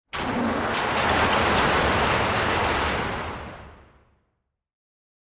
Garage Door
fo_garagedr_lg_close_02_hpx
Large and small garage doors are opened and closed.